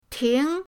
ting2.mp3